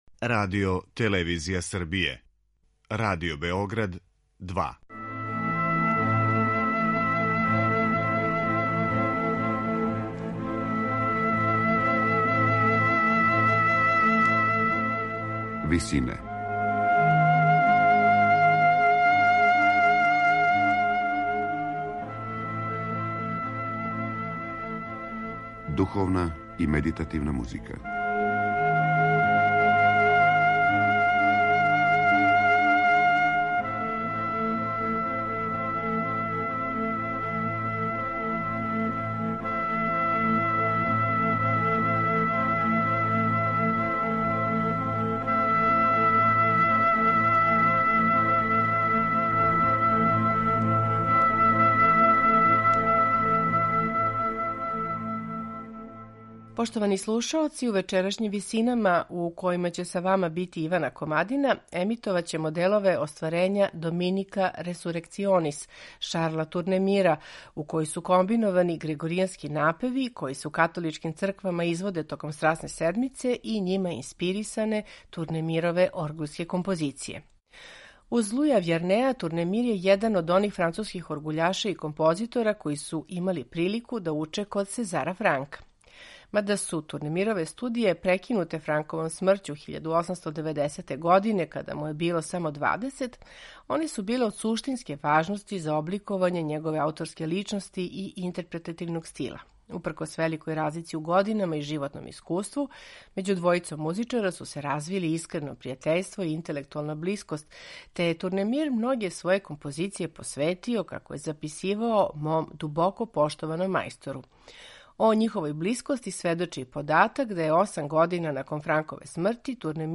Осам година након Франкове смрти, Турнемир је наследио професорову позицију оргуљаша у базилици Свете Клотилде и на том месту остао читавог живота.
своје релативно кратке оргуљске комаде и грегоријанске напеве намењене служби током Страсне седмице
хора